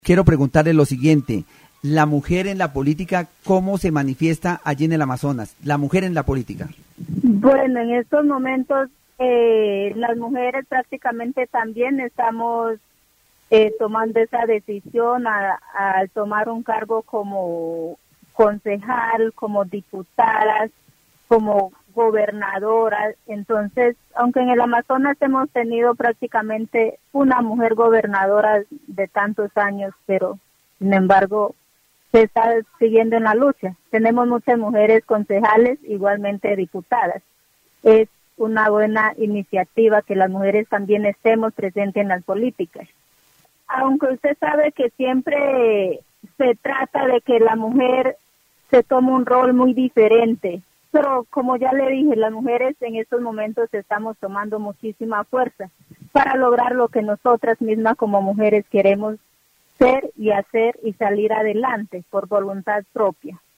El programa aborda el papel de la mujer en la política en el Amazonas. La entrevistada destaca que, aunque históricamente ha habido poca representación femenina en cargos de alto nivel, actualmente las mujeres están asumiendo roles de liderazgo como concejalas, diputadas y gobernadoras. Se enfatiza el esfuerzo continuo de las mujeres por fortalecer su presencia en la política y superar los obstáculos sociales que han limitado su participación.